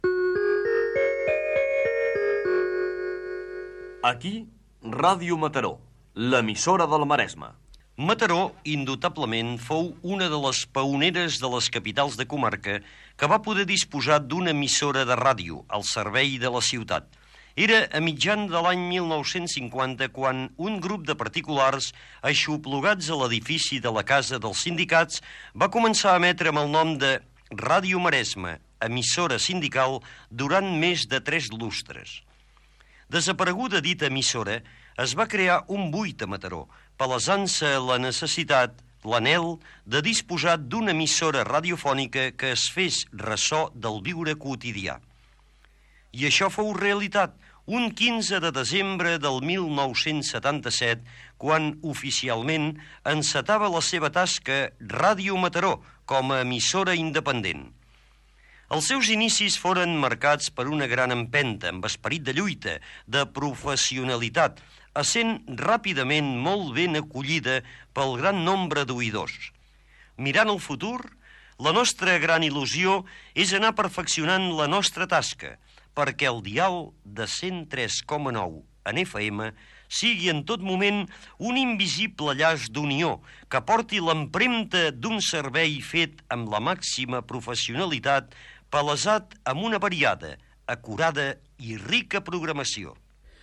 Indicatiu de Ràdio Mataró.
Entreteniment